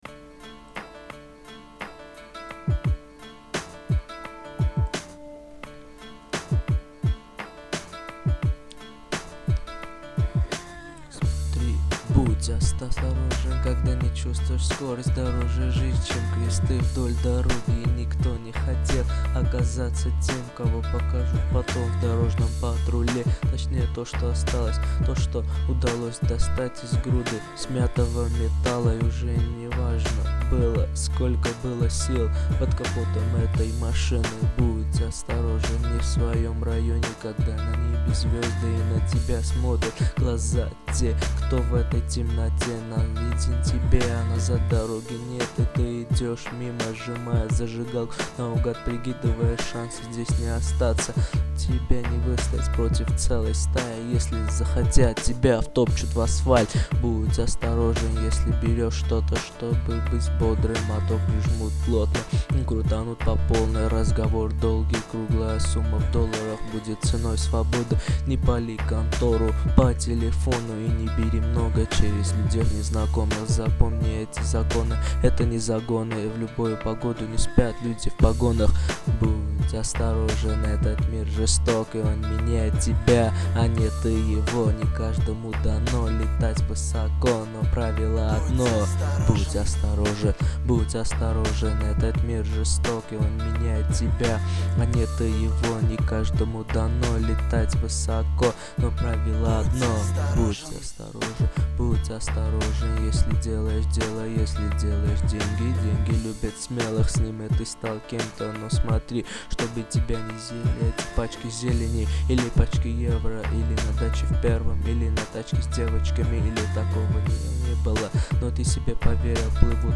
Категория: Реп/Rap